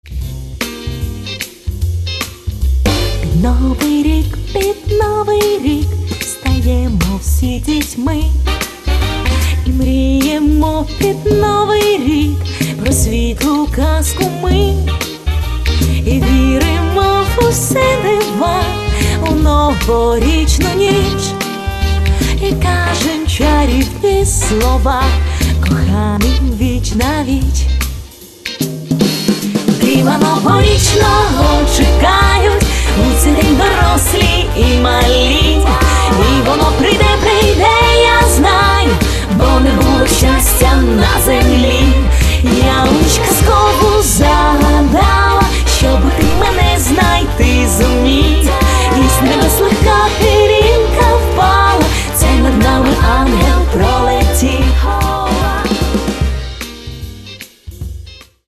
тож настрій по собі альбом залишає світлий.